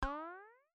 Cute pop2.wav